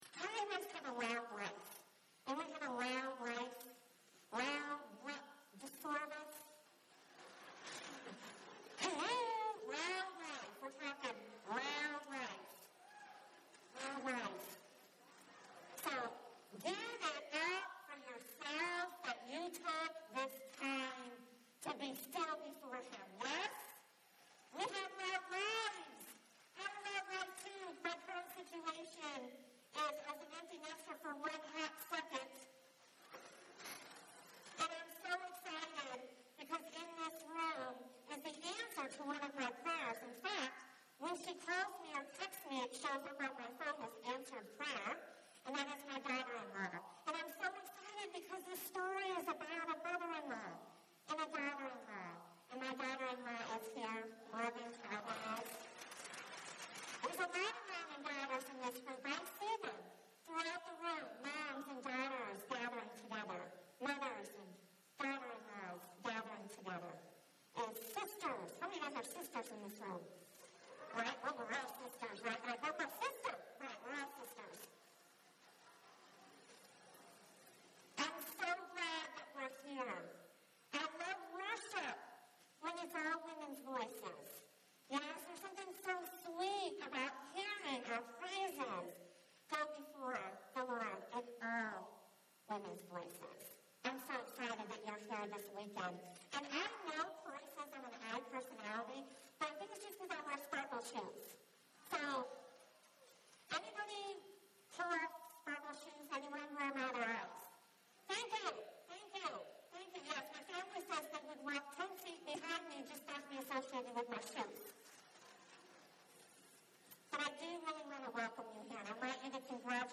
special message
2019 Women's Retreat